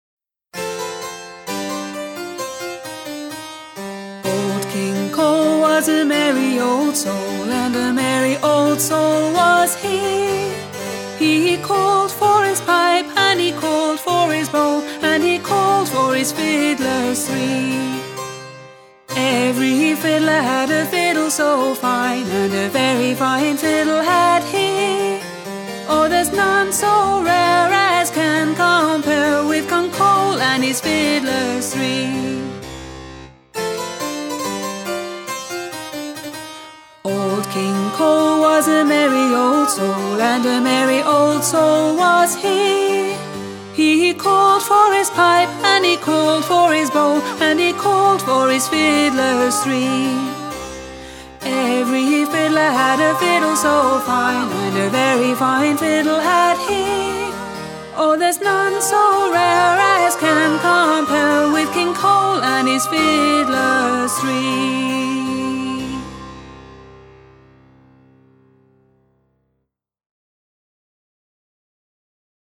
TraditionalFolk